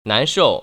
[nánshòu] 난서우  ▶